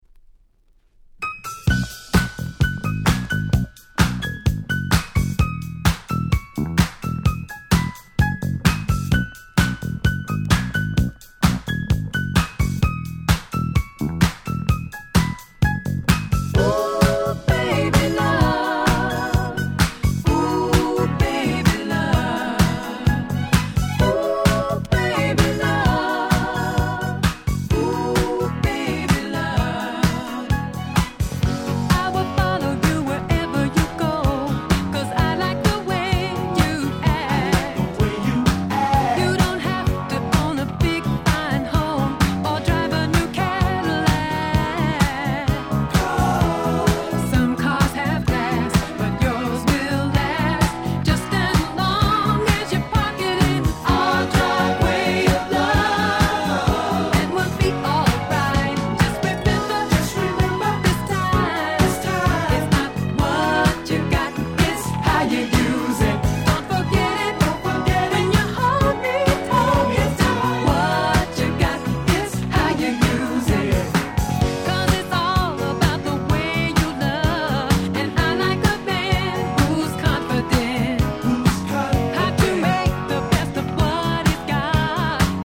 80' Nice Disco Boogie !!